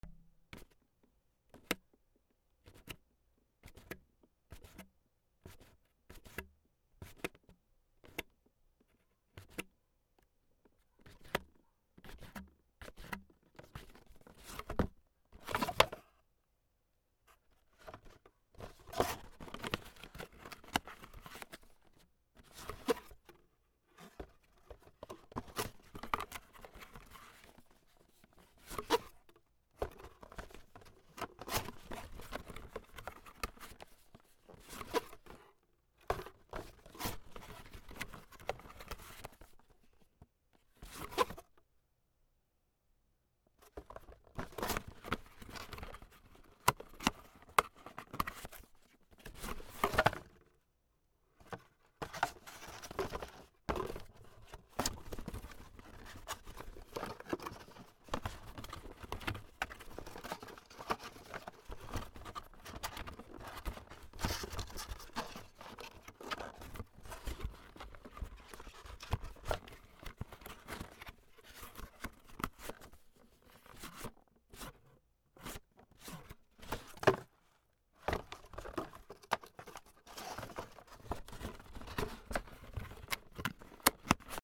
箱を開け閉め
/ K｜フォーリー(開閉) / K56 ｜小物の開け閉め